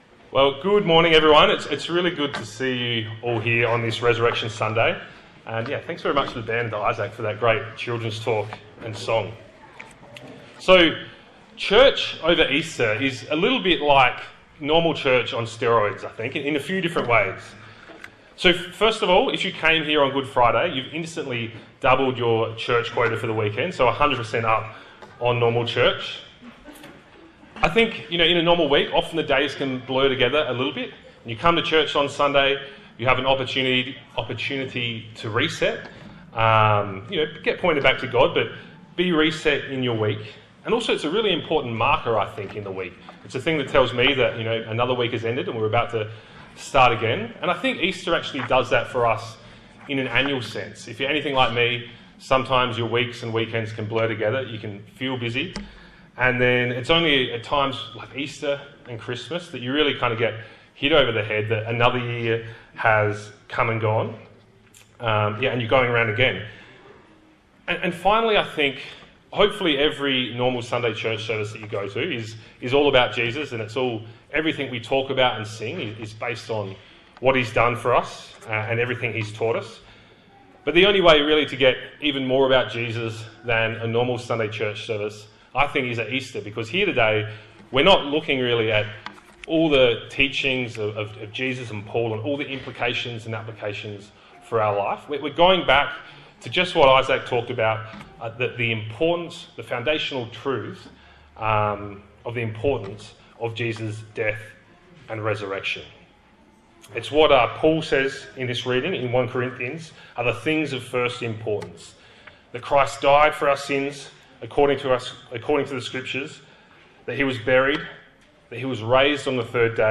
Easter Day 2025 Passage: 1 Corinthians 15:1-11 Service Type: Easter Sunday